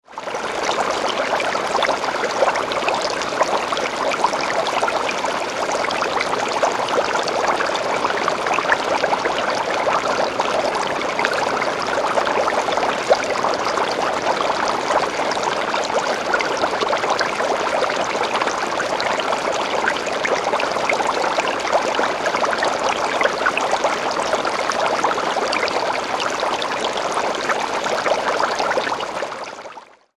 Boiling Liquid; Bubbles 2; A Rapid Liquid Boil; Consistency Of Water, Close Perspective.